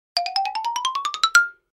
BOOYOOY-11 - Bouton sonore